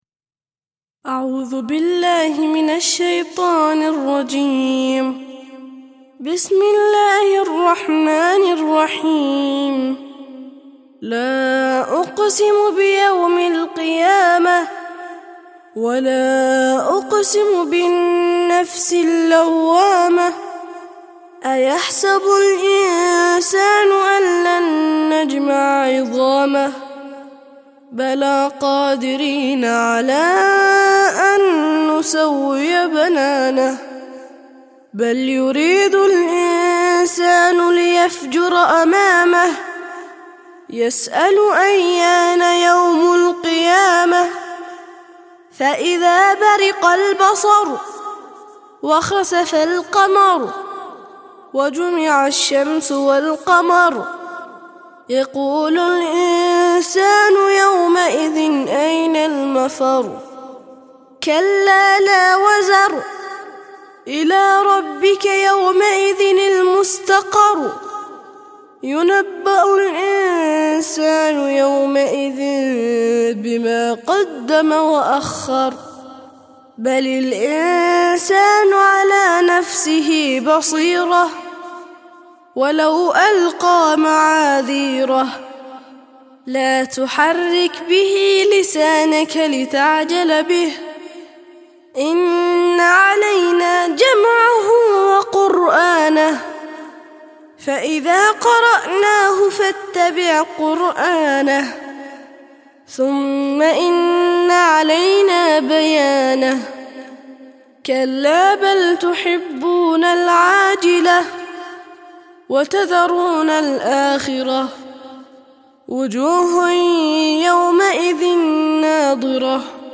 75- سورة القيامة - ترتيل سورة القيامة للأطفال لحفظ الملف في مجلد خاص اضغط بالزر الأيمن هنا ثم اختر (حفظ الهدف باسم - Save Target As) واختر المكان المناسب